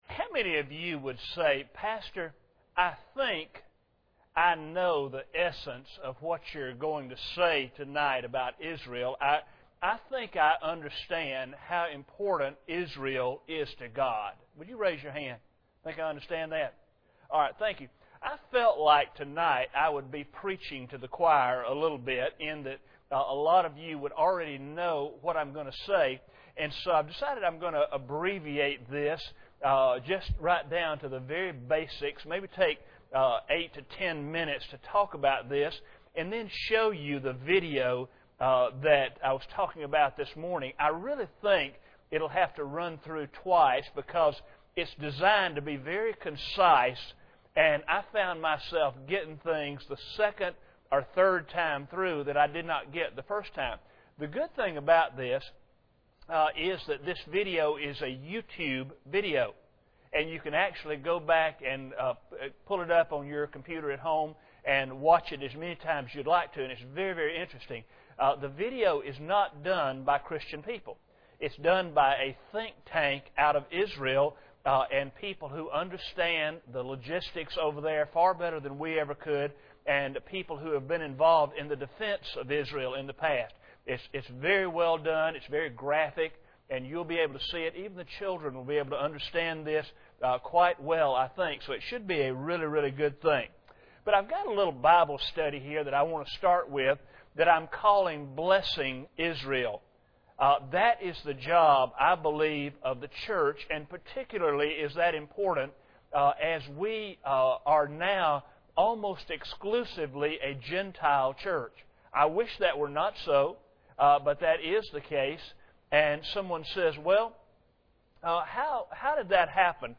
General Service Type: Sunday Evening Preacher